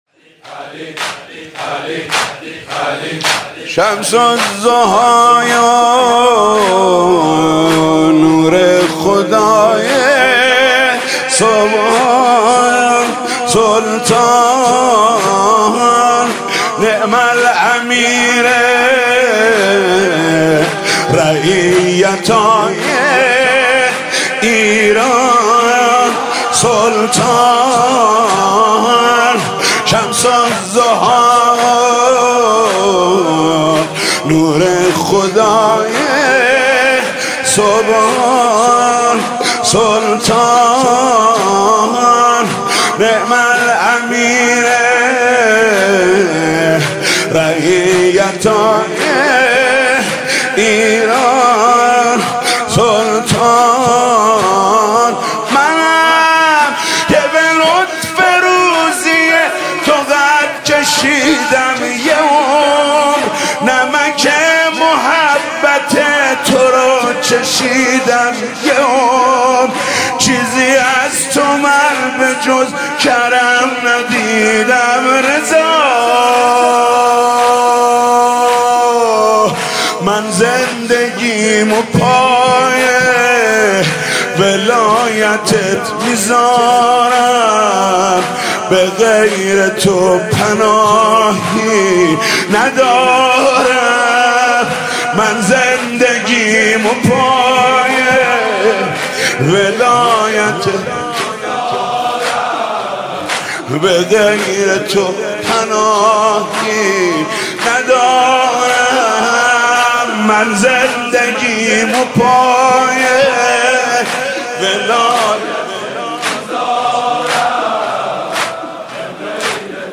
صوت/مداحی حاج محمود کریمی بمناسبت شهادت جانسوز امام رضا (علیه السلام) - تسنیم
صوت مداحی و عزاداری حاج محمود کریمی بمناسبت شهادت جانسوز حضرت شمس الشموس علی ابن موسی الرضا (علیه السلام) منتشر می شود.